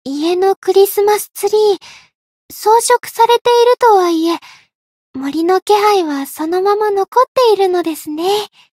灵魂潮汐-伊汐尔-圣诞节（摸头语音）.ogg